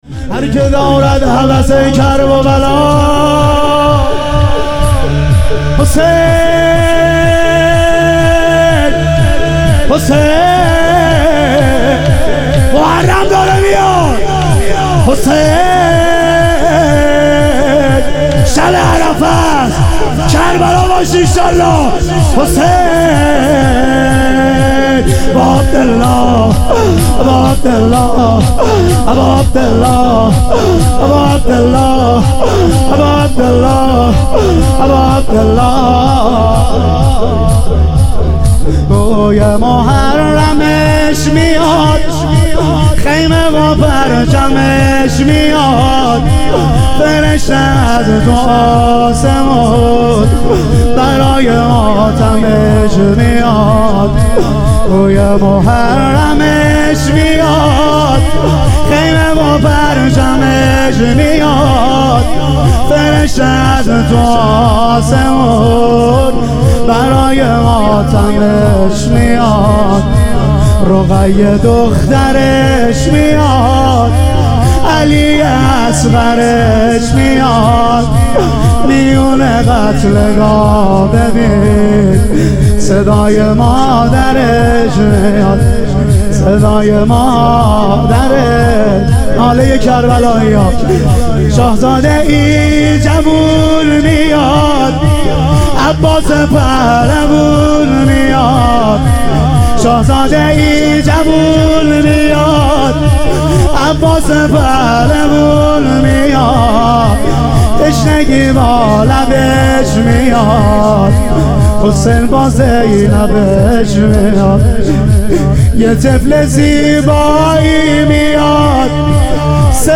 شهادت حضرت مسلم علیه السلام - شور